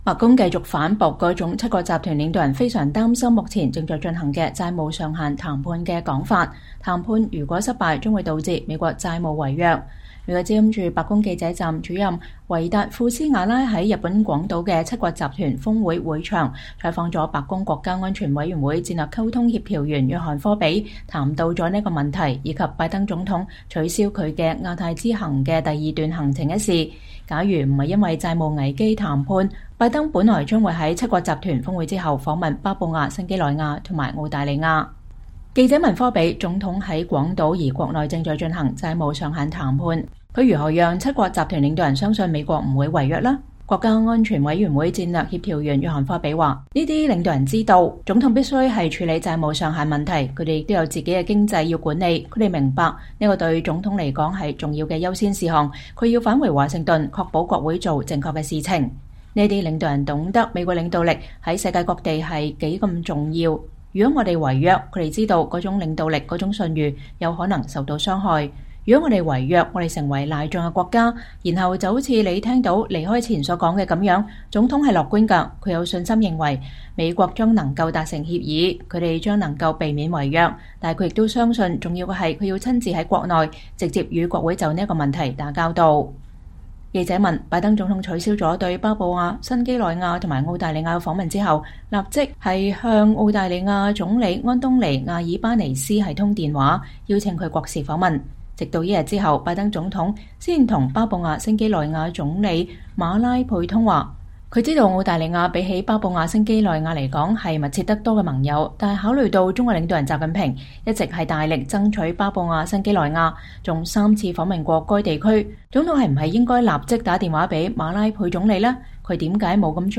VOA專訪美國安會發言人 談七國集團峰會、債務上限危機等議題